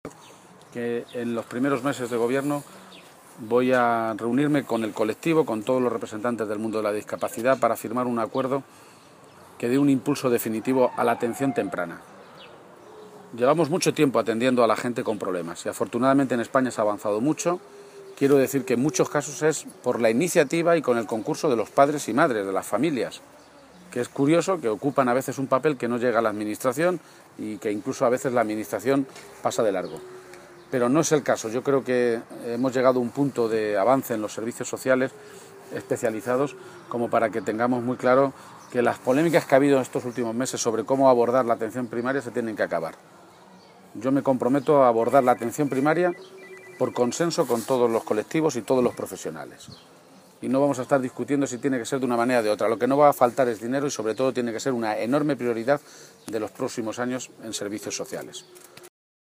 García-Page se pronunciaba de esta manera esta mañana, en Yepes, en la provincia de Toledo, donde tiene su sede y su centro de Atención AMAFI, una Asociación para el Cuidado Integral de las personas con discapacidad intelectual.
Allí ha recorrido las instalaciones acompañado por el equipo directivo y, tras finalizar la visita y en una atención a los medios de comunicación, ha sostenido que centros de este tipo «hacen que una salga más moralizado que cuando entró».